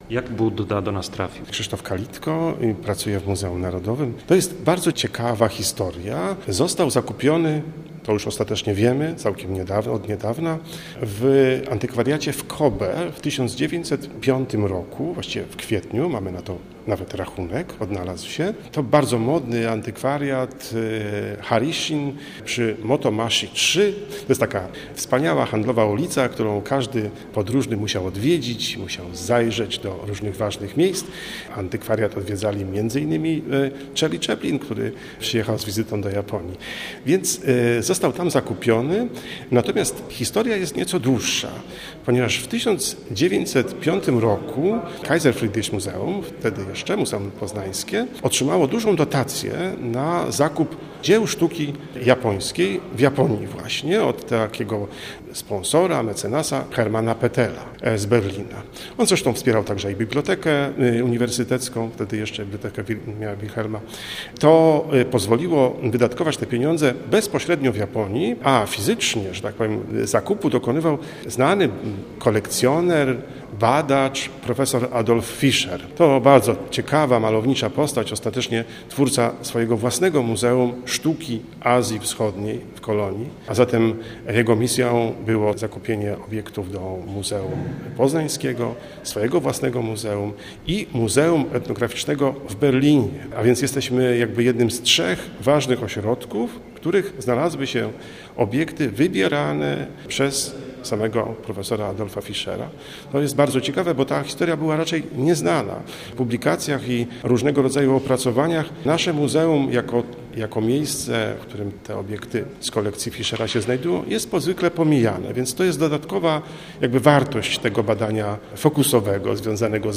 Zapis rozmowy: